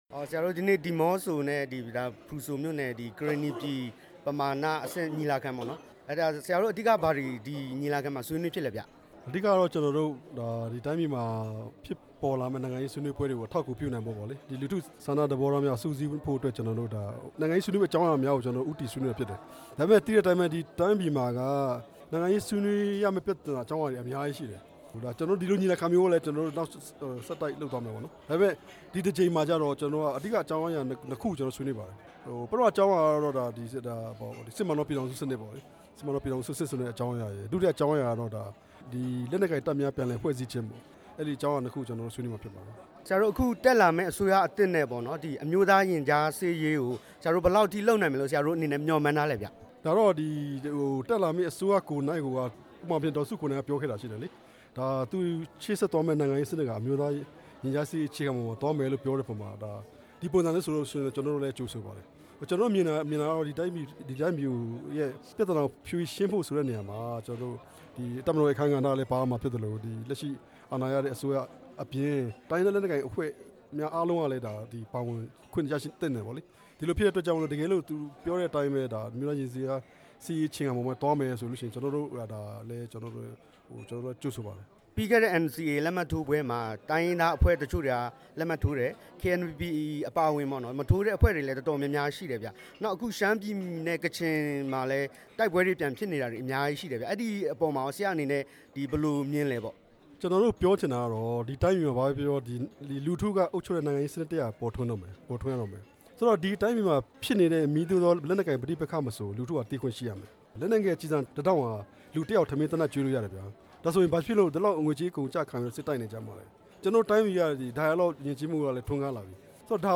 တွေ့ဆုံမေးမြန်းထားပါတယ်။